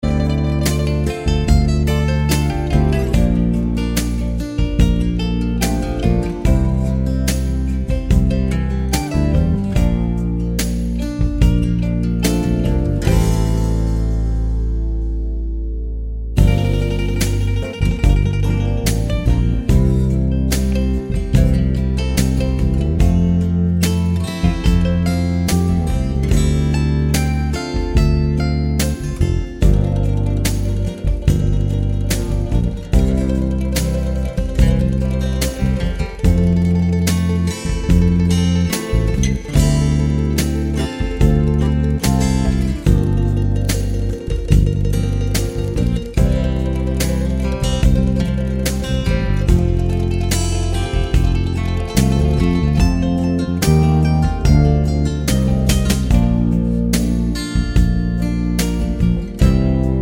no Backing Vocals Easy Listening 4:47 Buy £1.50